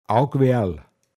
pinzgauer mundart
Augweal, n. Gerstenkorn